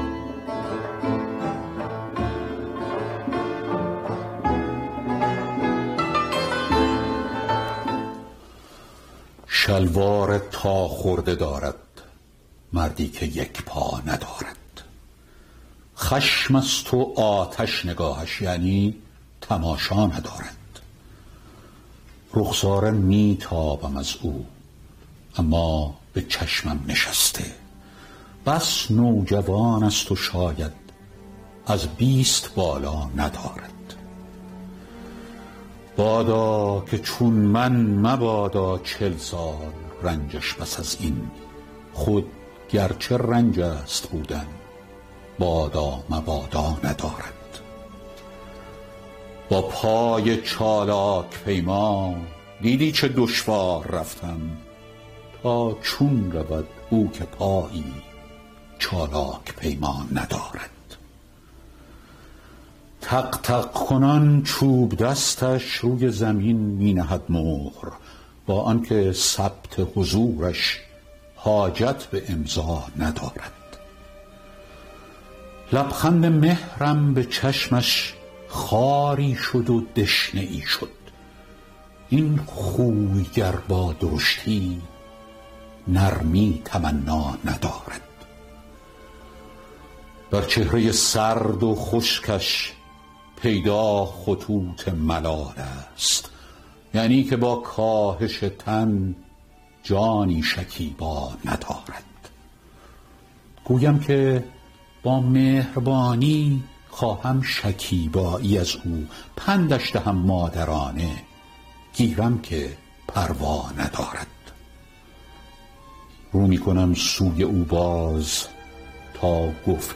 دانلود دکلمه مردی که یک پا ندارد با صدای فریدون فرح اندوز
اطلاعات دکلمه
گوینده :   [فریدون فرح اندوز]